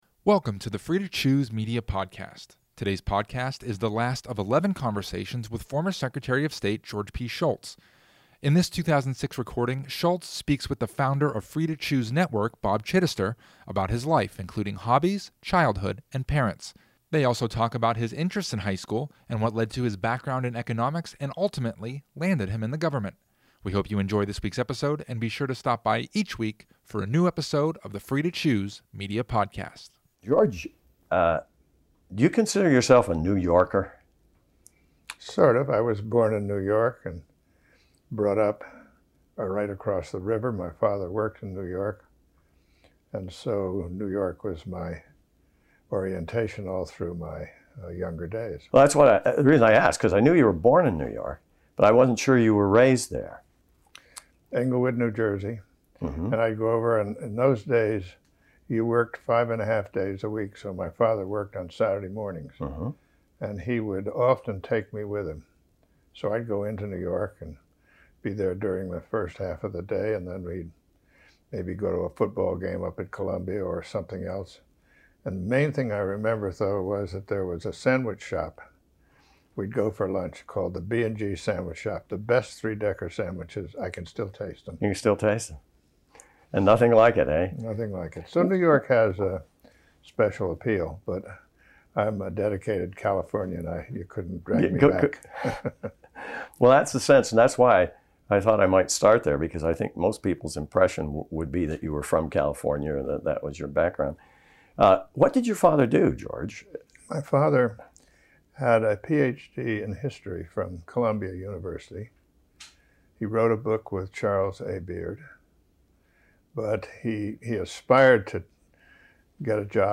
Throughout the conversation, Shultz speaks about his hobbies, childhood, parents, and upbringing through high school. Shultz talks about his interests in high school as well as how he got into economics, ultimately leading him to his career in government.